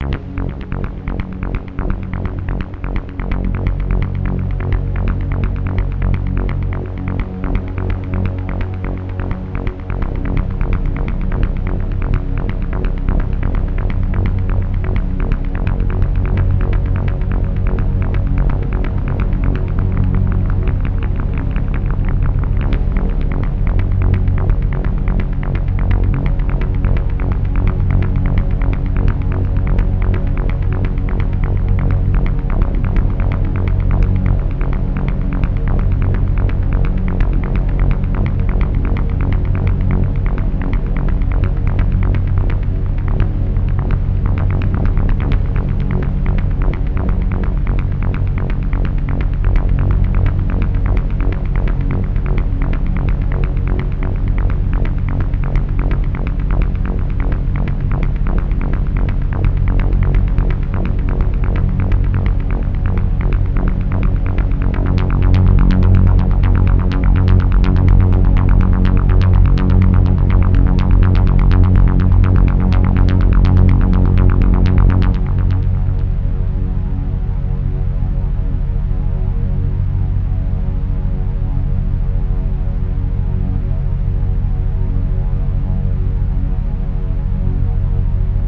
Lots of rhythm going on.